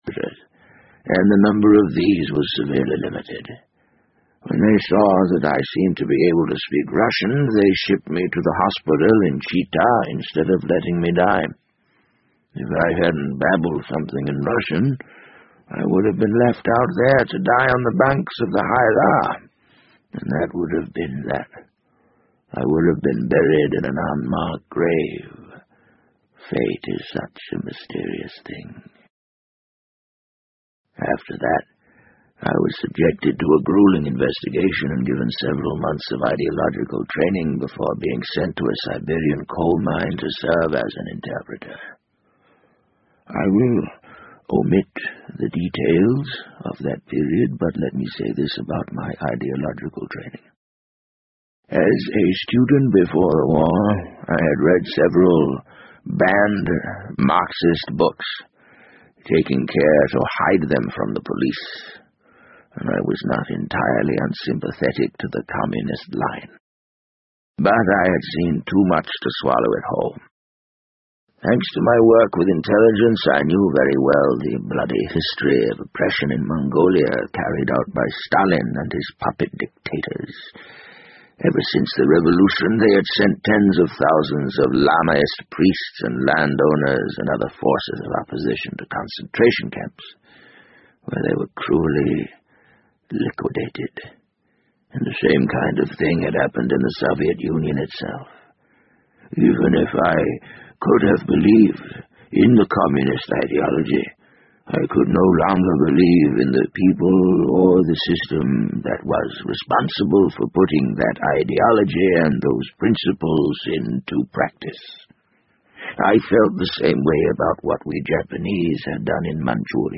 BBC英文广播剧在线听 The Wind Up Bird 014 - 2 听力文件下载—在线英语听力室